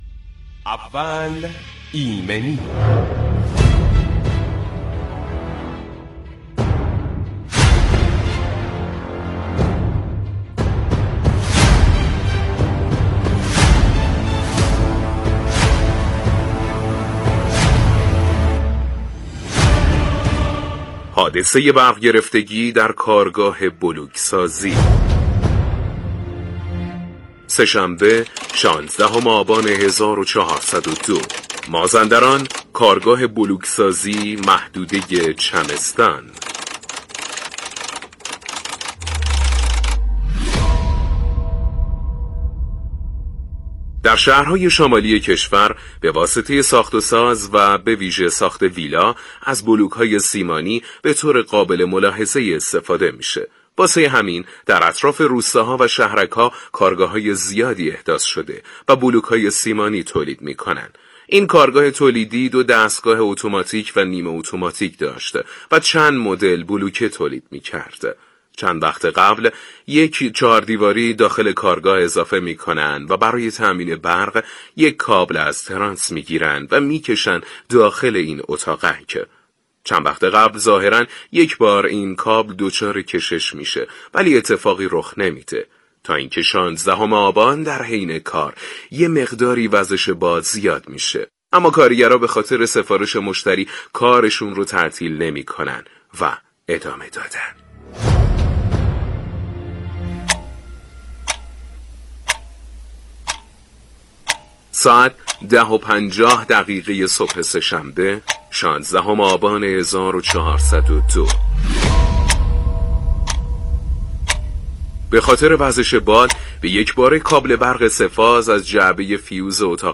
گوش کنید به پادکست اول ایمنی — تحلیل تخصصی حادثه برق‌گرفتگی در کارگاه بلوک‌سازی با حضور کارشناس HSE برای افزایش آگاهی و پیشگیری از حوادث مشابه.
برنامه اول ایمنی به مدت ۱۵ دقیقه با حضور کارشناس متخصص آغاز و تجربیات مصداقی ایمنی صنعتی به صورت داستانی بیان می شود.